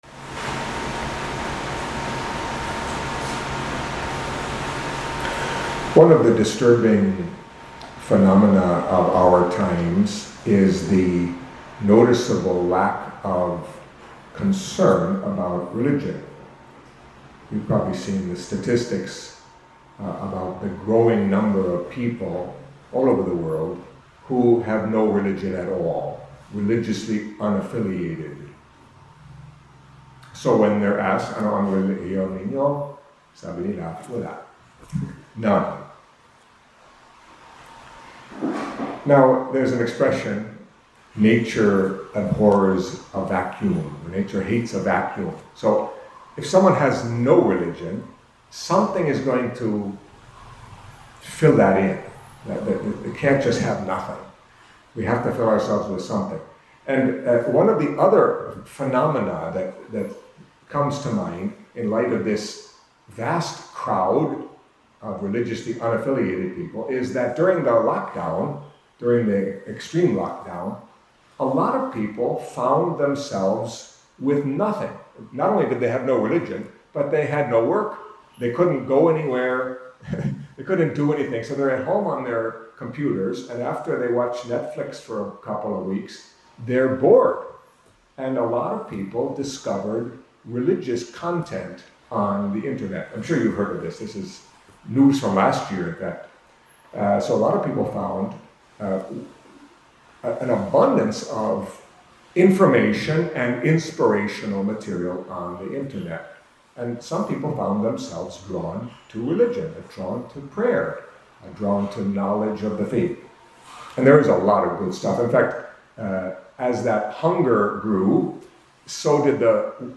Catholic Mass homily for Wednesday of the 16th Week in Ordinary Time